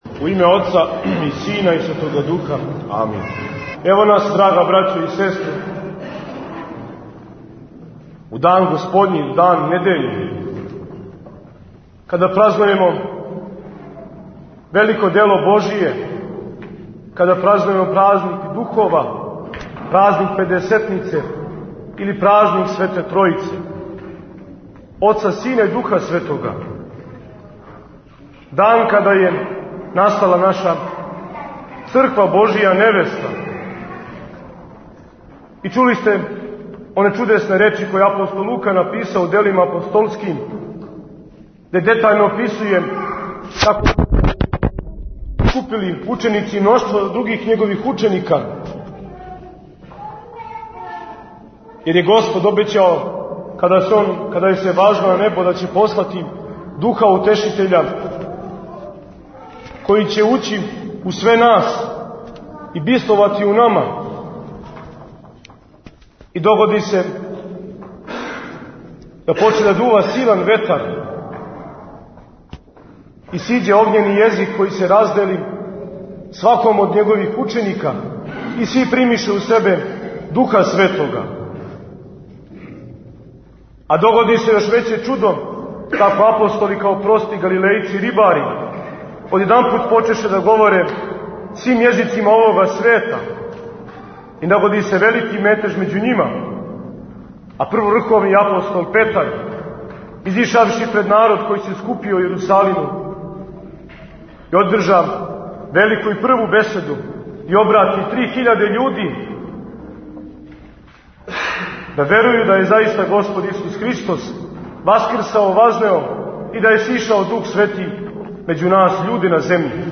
Бесједе